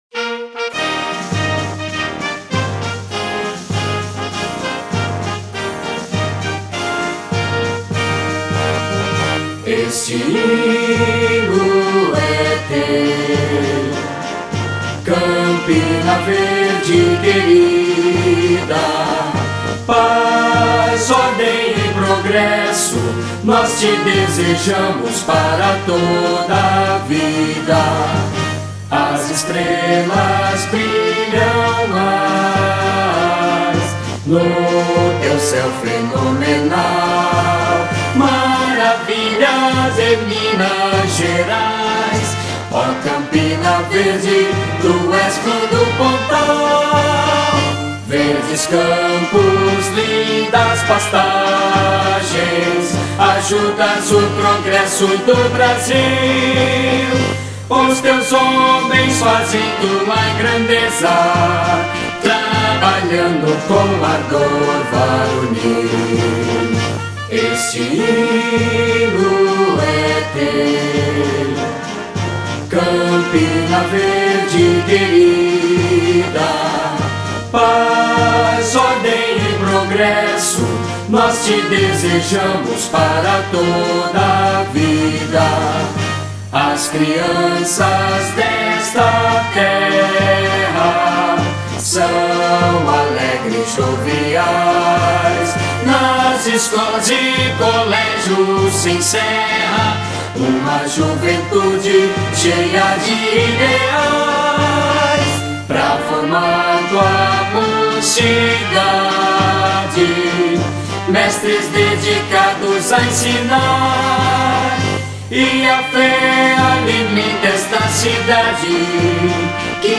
Hino Campina Verde - Cantado